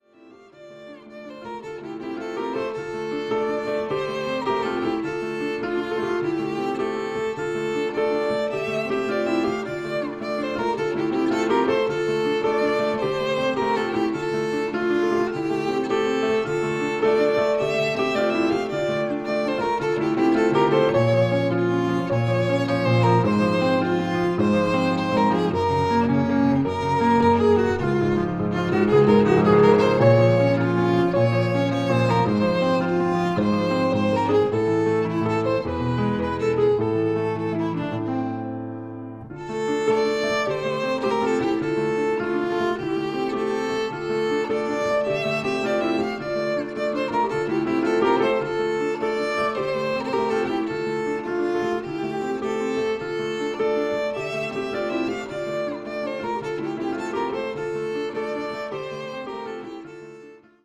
pianoforte
primo violino
una ballata dal vago sapore medievale